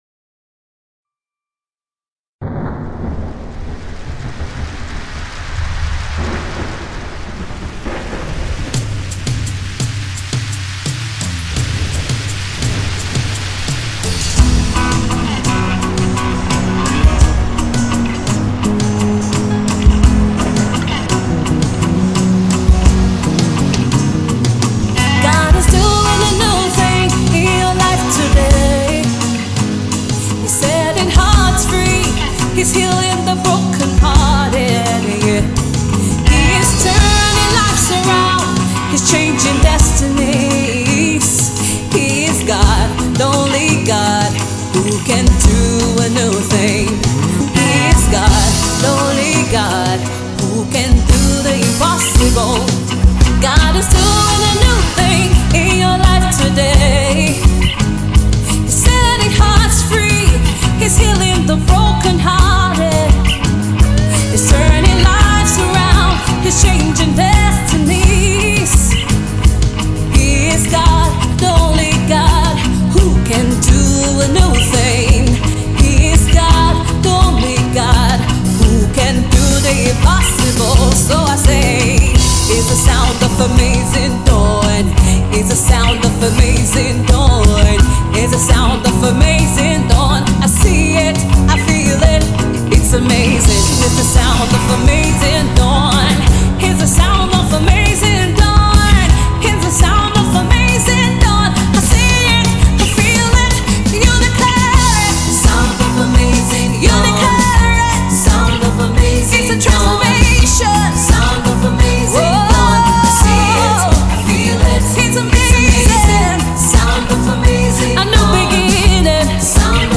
Sensational Gospel Minister